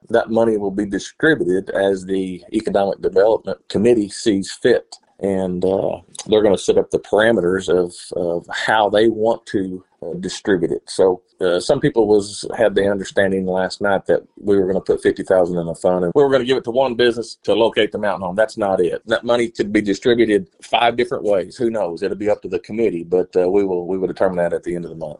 County Judge Kevin Litty, spoke with KTLO News to discuss the parameters of the fund.